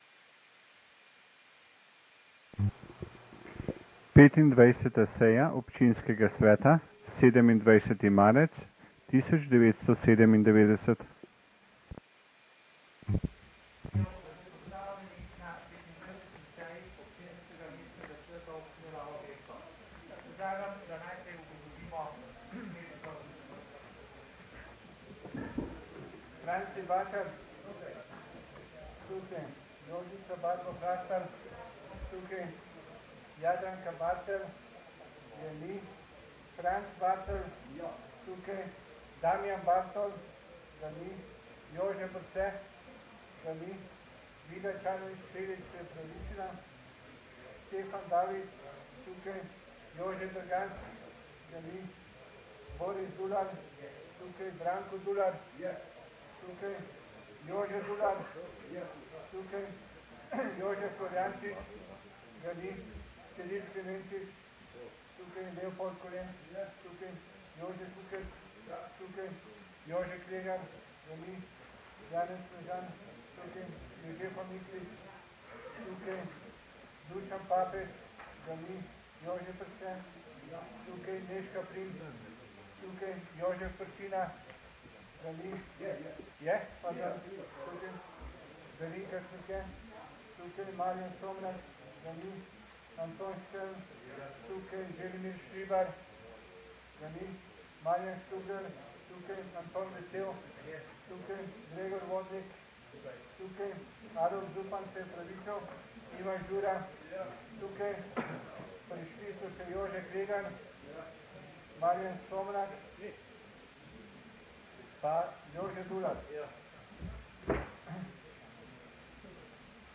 25. seja Občinskega sveta Mestne občine Novo mesto - Seje - Občinski svet - Mestna občina